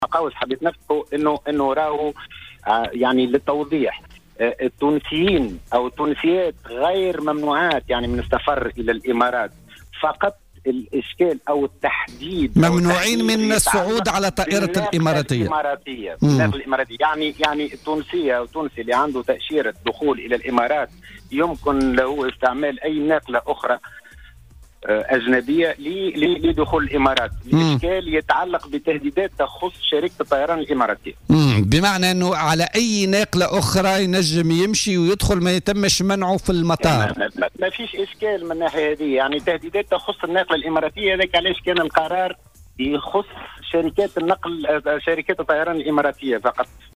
خلال مداخلة هاتفيه له في برنامج 'بوليتيكا'، أن التونسيات غير ممنوعات من السفر إلى الإمارات.